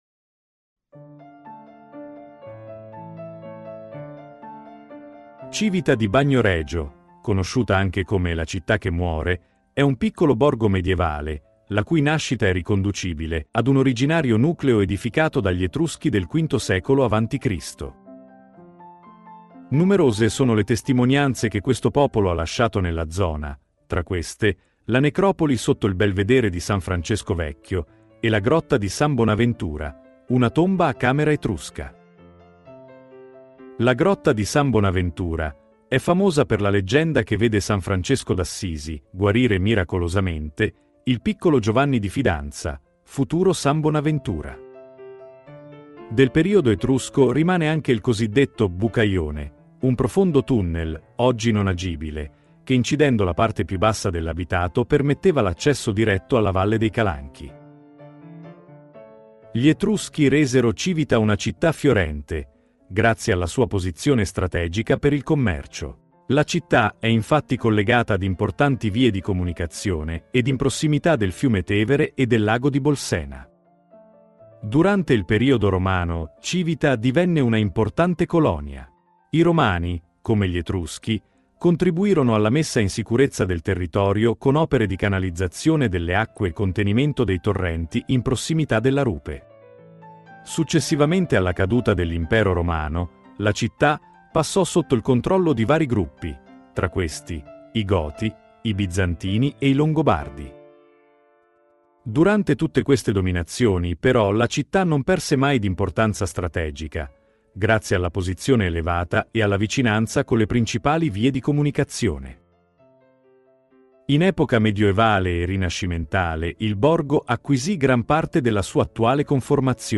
Audioguida Civita di Bagnoregio – Cenni Storici